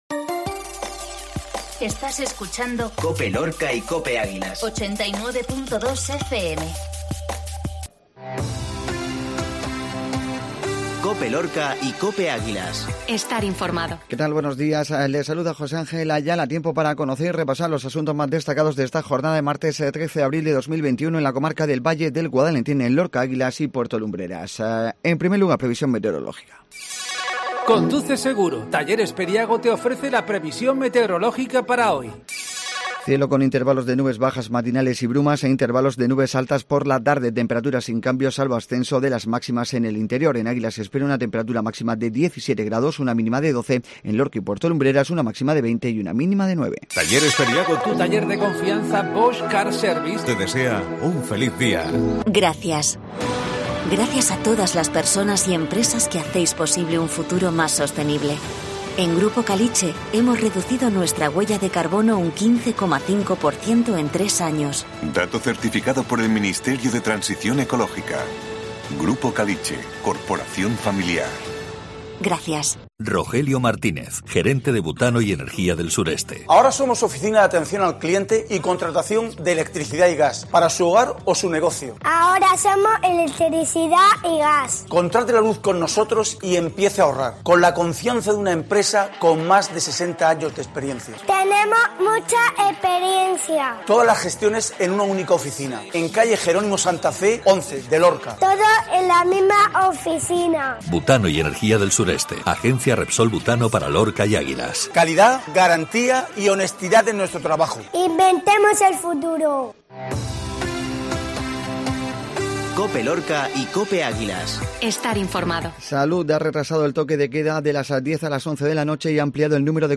INFORMATIVO MATINAL MARTES